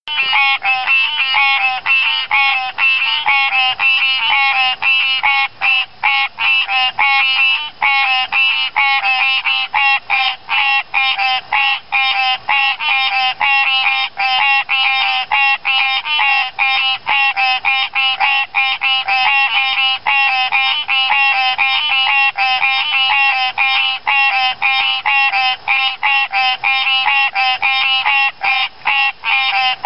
If you want a few nice, clear "croaks" rather than a maddening crowd of froggage, this one is for YOU! This is Hyla cinerea, and is a simply-adorable little ringtone!